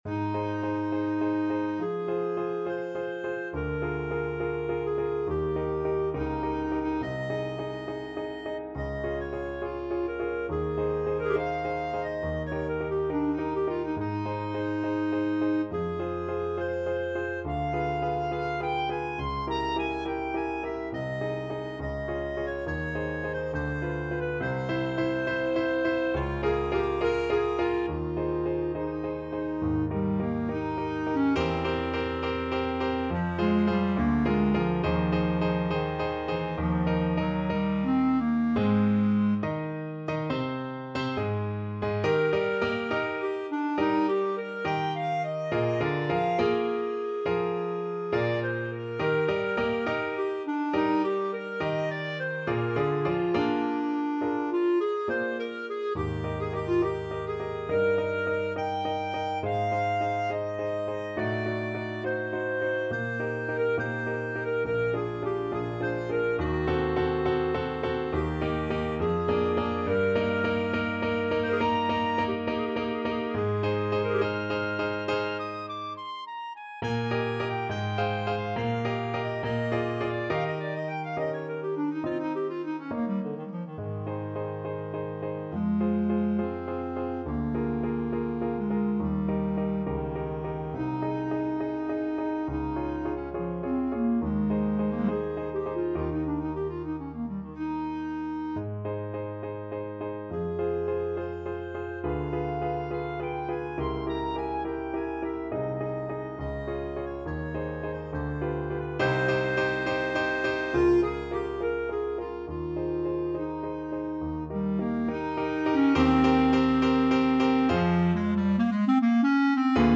Clarinet
Ab major (Sounding Pitch) Bb major (Clarinet in Bb) (View more Ab major Music for Clarinet )
4/4 (View more 4/4 Music)
Classical (View more Classical Clarinet Music)